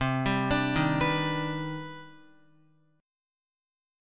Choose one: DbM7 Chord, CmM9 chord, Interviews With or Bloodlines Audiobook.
CmM9 chord